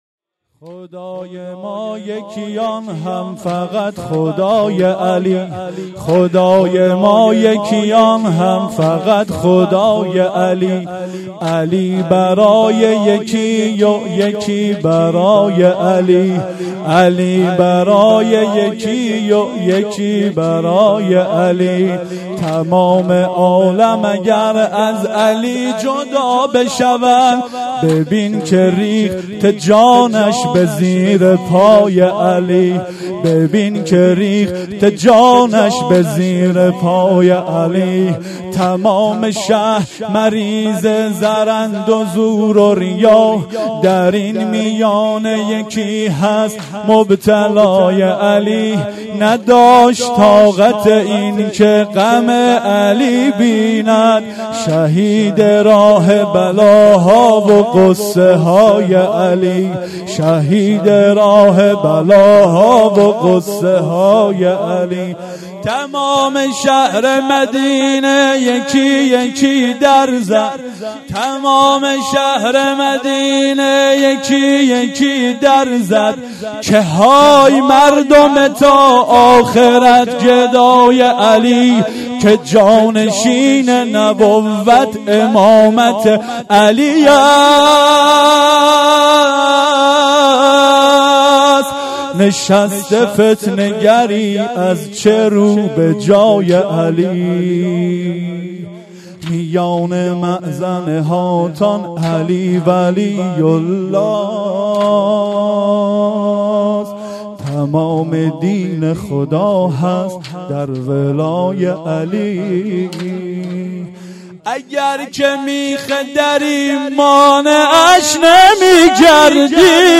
ظهر شهادت حضرت فاطمه زهرا (س) 1395